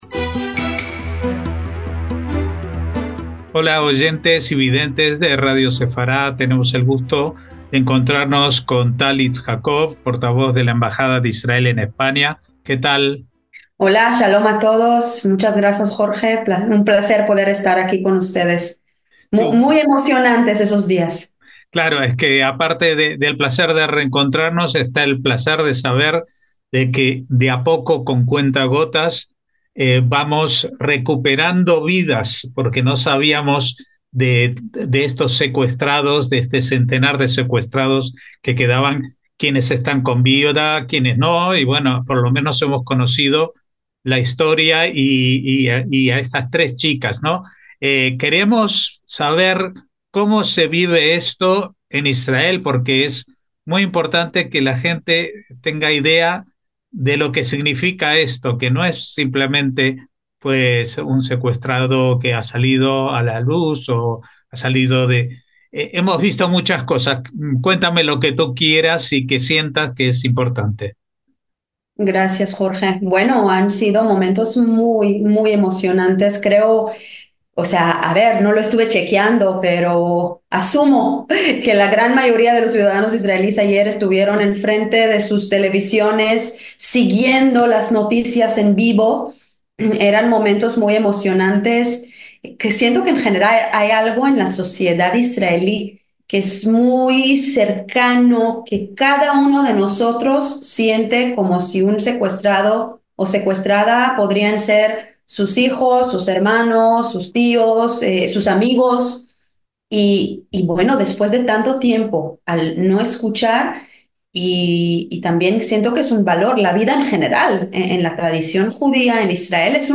ESPECIAL NOTICIAS - Hace poco más de 24 horas se produjo la liberación de tres jóvenes mujeres secuestradas por Hamás el fatídico 7 de octubre de 2023 en la zona aledaña a la franja de Gaza.